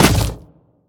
biter-roar-behemoth-9.ogg